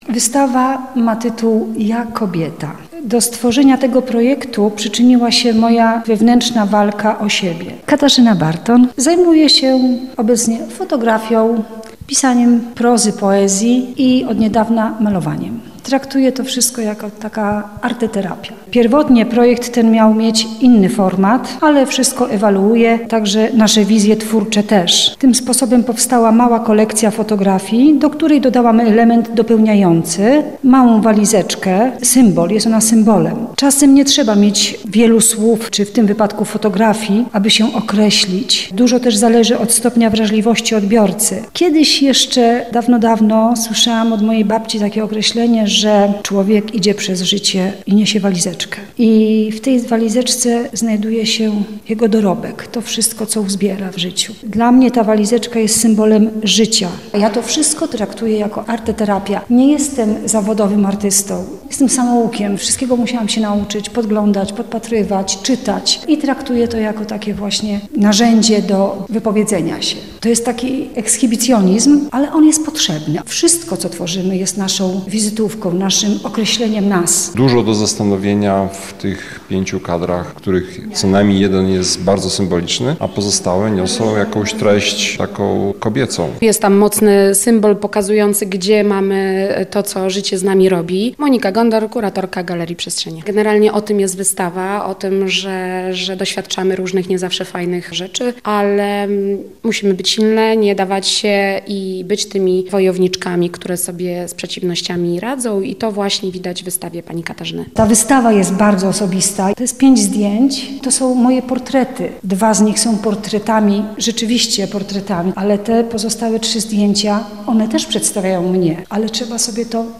WYSTAWA-_JA-KOBIETA_-wywiad.mp3